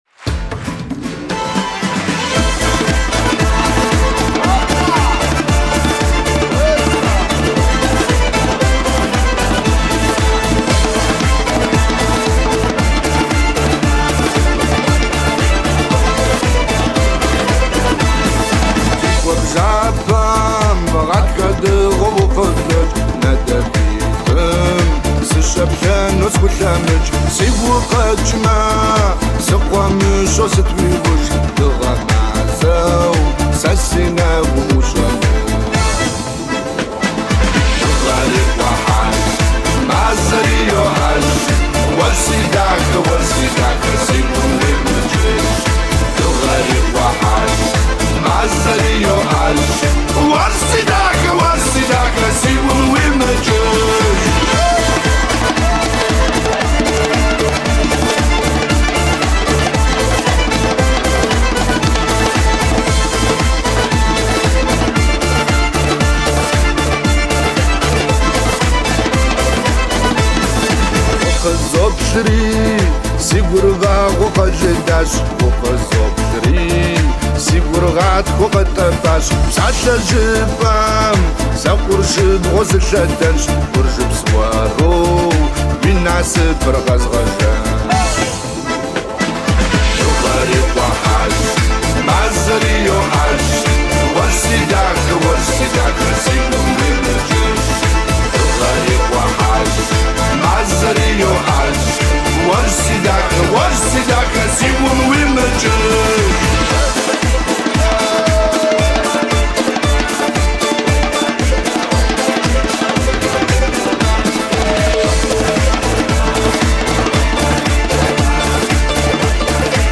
Адыгская музыка